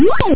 Amiga 8-bit Sampled Voice
sample04.whoop_.mp3